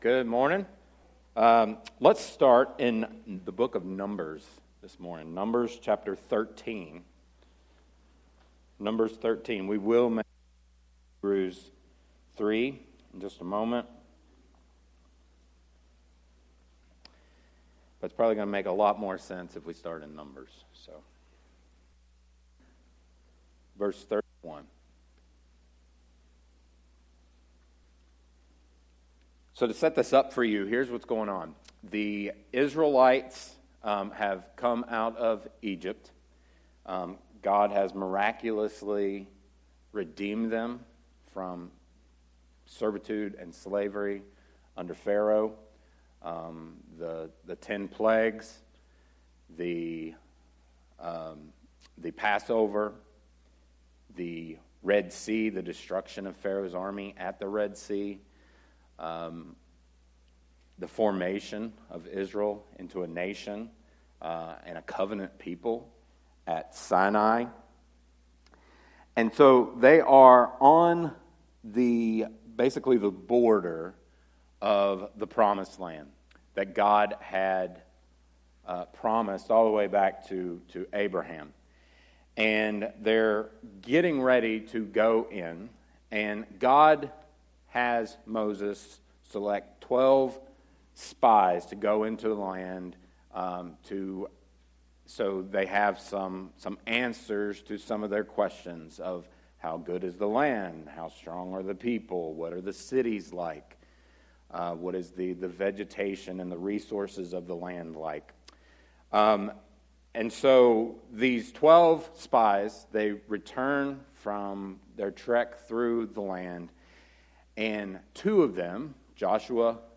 sermon-audio-trimmed-3.mp3